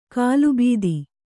♪ kālu bīdi